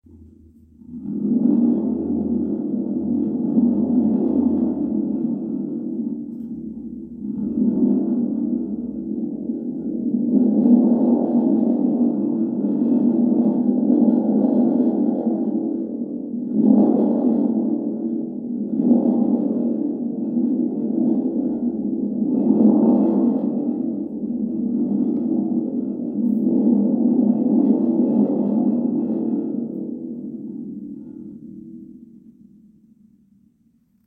Thunder Sound
• Deep, rumbling thunder-like resonance for grounding and clearing
Introducing the Thunder Box, a powerful and unique sound healing instrument designed to create the deep, resonant sound of rolling thunder.
Simply shake the Thunder Box to produce a heavy, rumbling sound that mimics the natural vibrations of a thunderstorm, helping to ground and center your energy.
A: Hold and shake it gently — the deeper the motion, the more resonant the sound.
Thunder-sound.mp3